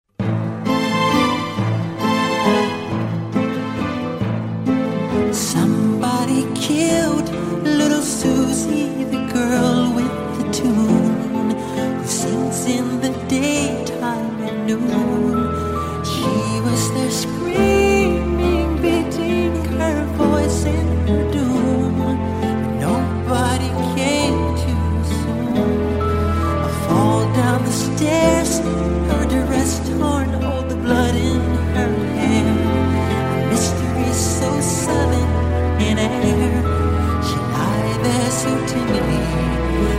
баллады